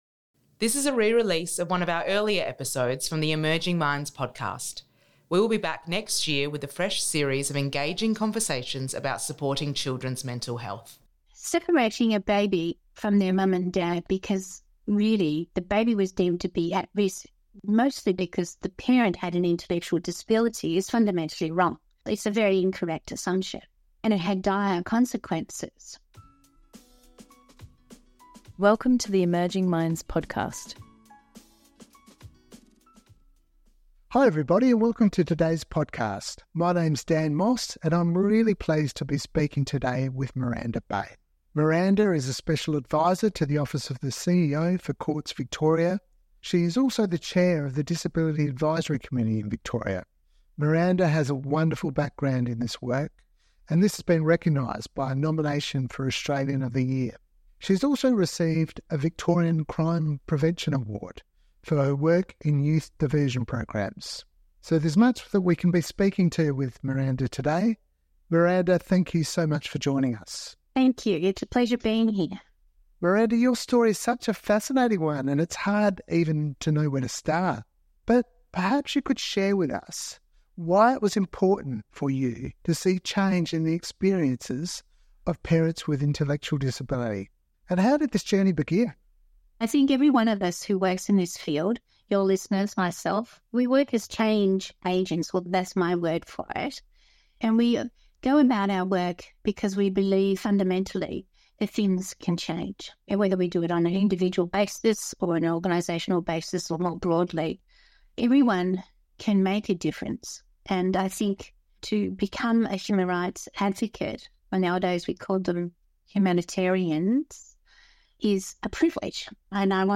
Listen to conversations with experts on a variety of topics related to children's mental health.